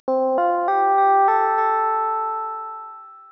III. Earcons
More abstract (than auditory icons) sonic events.
These are Hierarchical, and can be concatenated